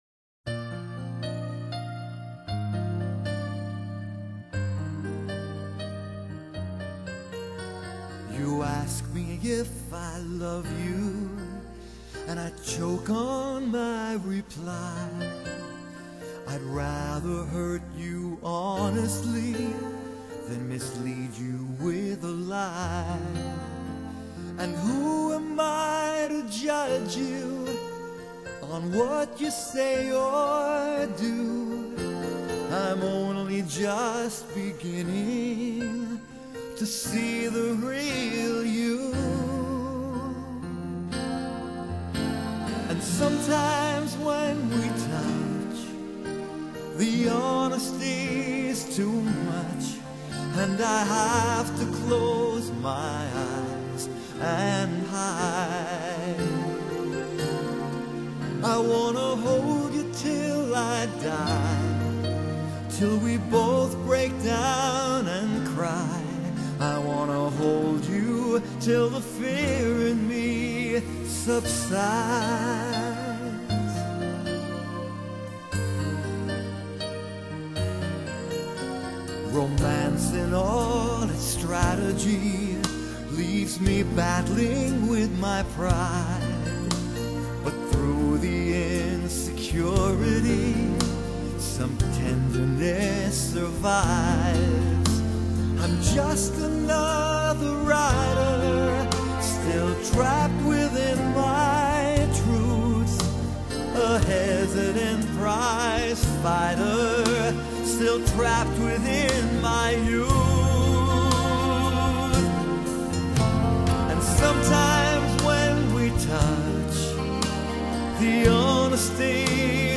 Genre: Pop, Adult Contemporary